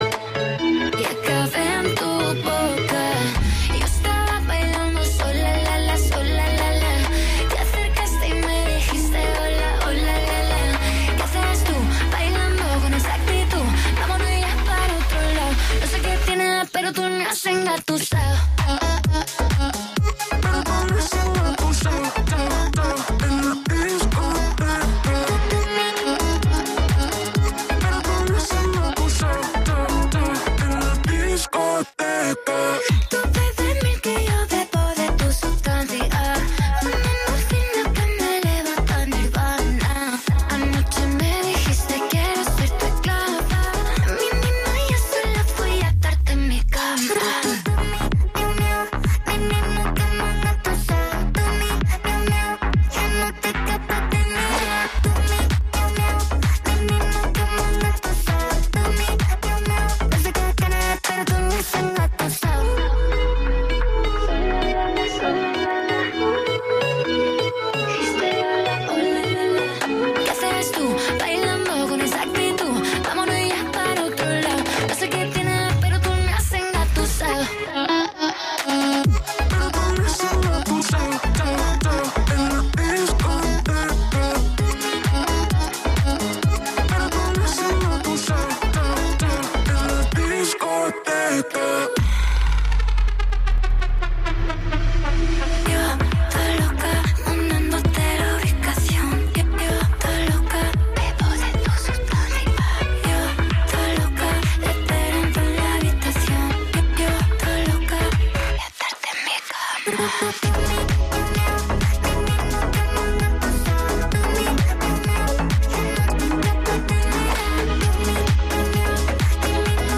Programa de lectura de contes